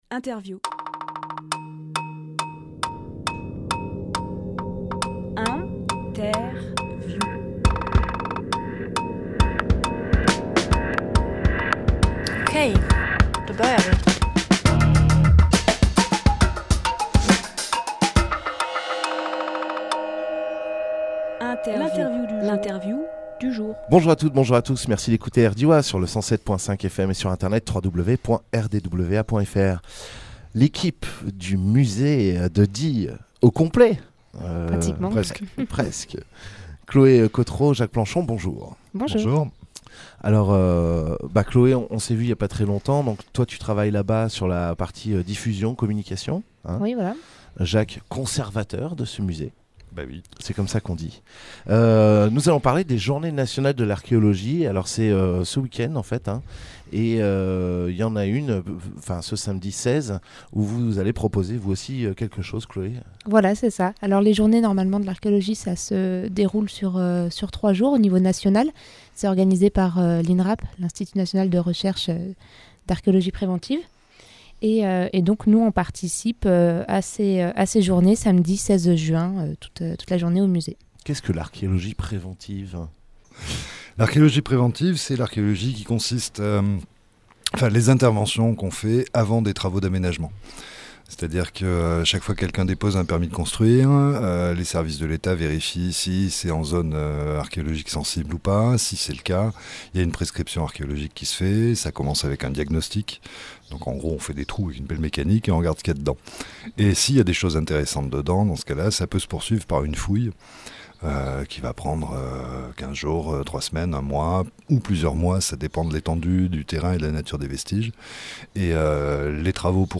Emission - Interview Journées Nationales de l’Archéologie au Musée de Die et du Diois Publié le 13 juin 2018 Partager sur…
Lieu : Studio RDWA